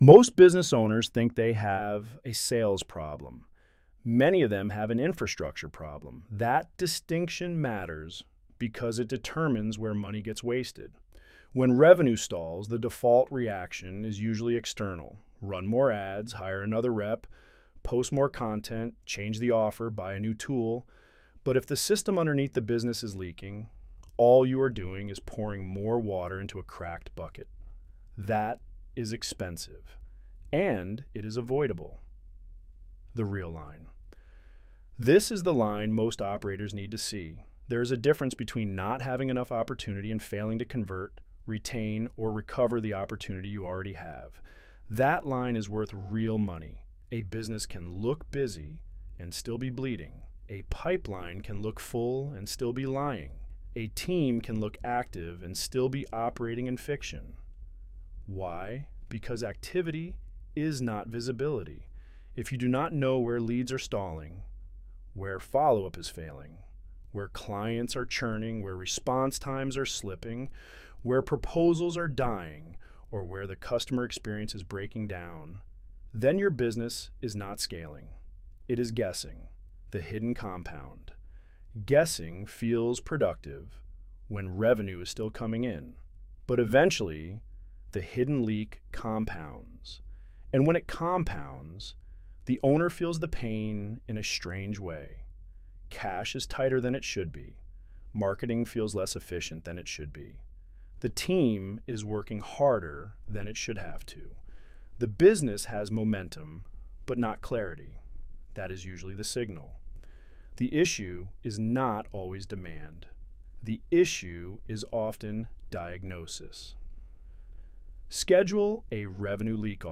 Narrated by the Author Download narration Most business owners think they have a sales problem.